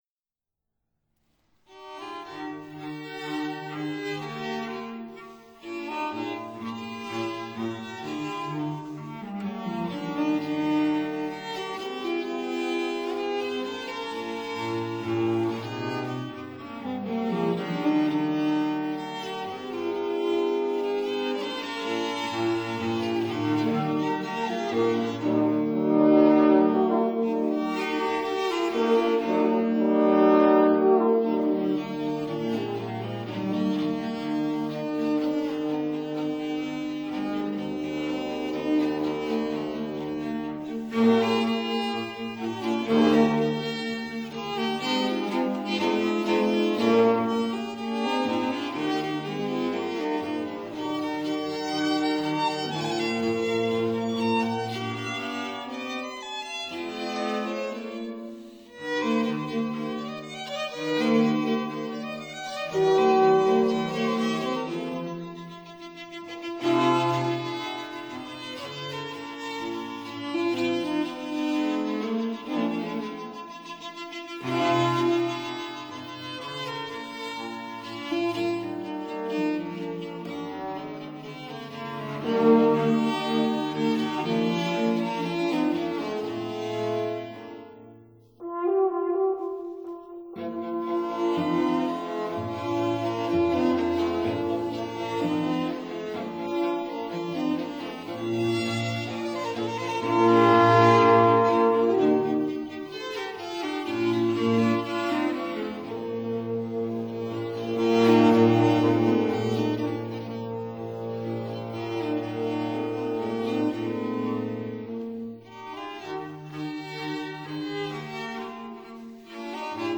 Divertimento for Gamba, Viola, Cello & 2 Horns
Sonata for Gamba, 2 Horns & Cello
Sextet for Gamba, Viloin, Viola, Cello & 2 Horns
(Period Instruments)